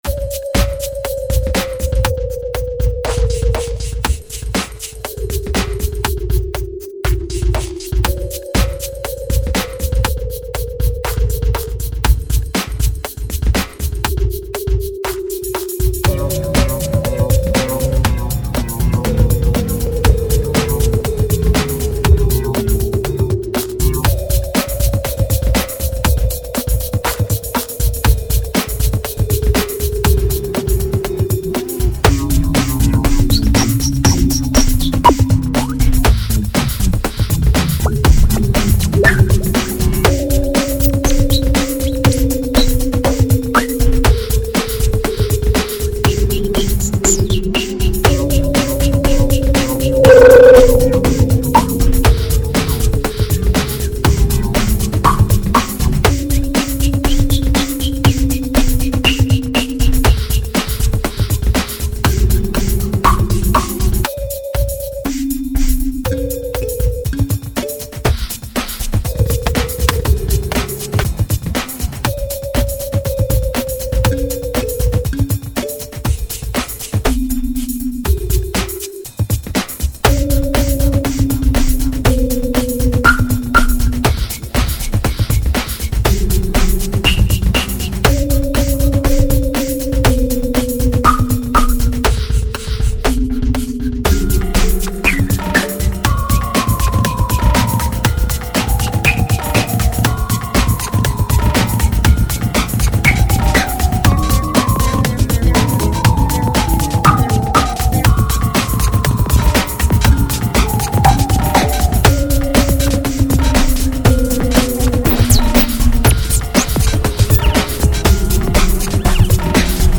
File under: Weird Electronica